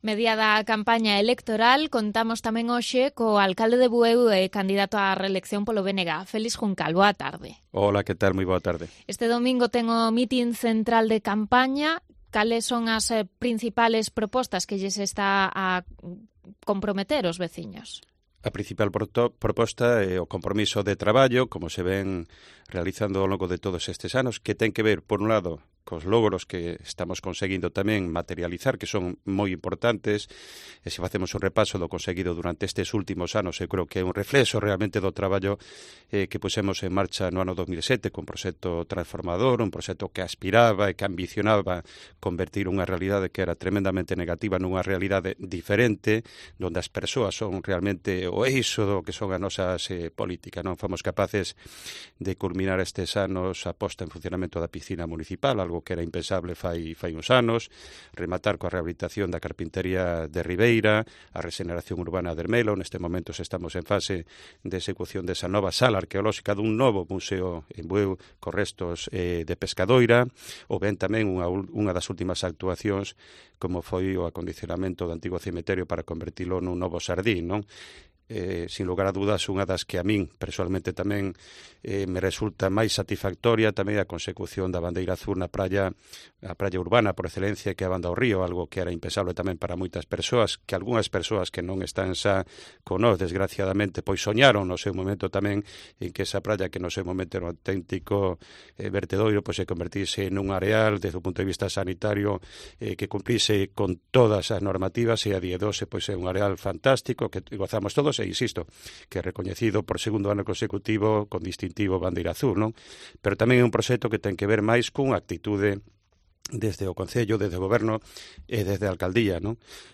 Entrevista al alcalde de Bueu y candidato a la reelección por el BNG, Félix Juncal